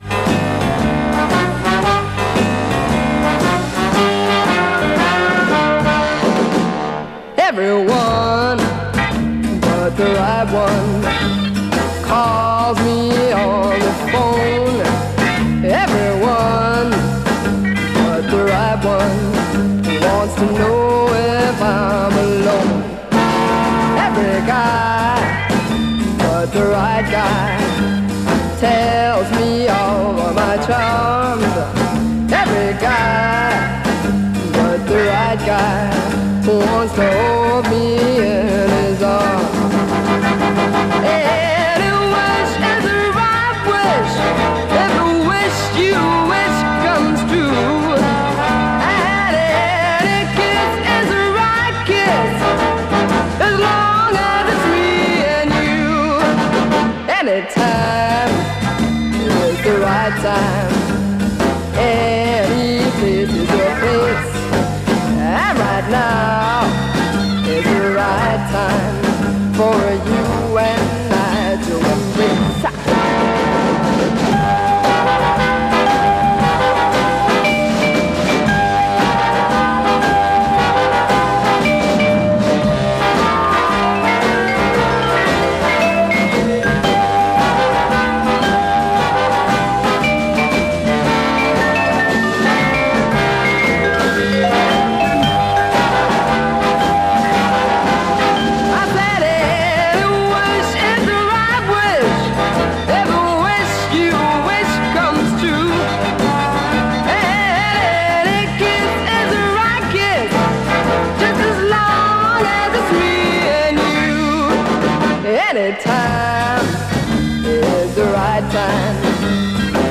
Female Garage Fuzz Mod Yéyé